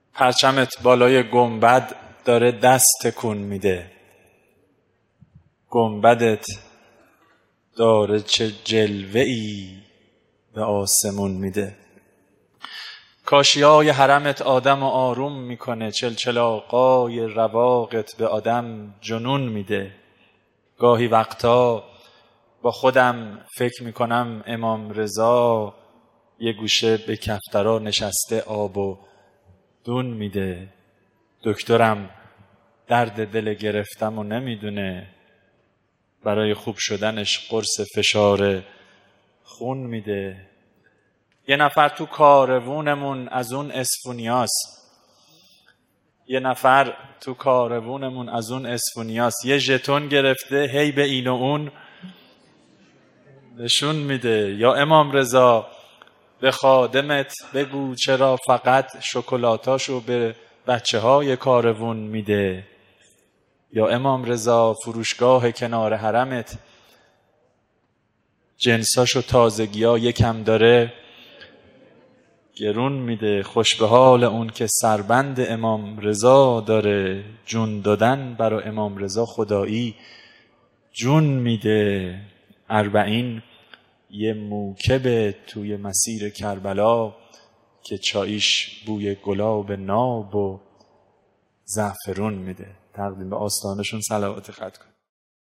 صوت قرائت همین ترانه زیبا را با صدای شاعر بشنوید: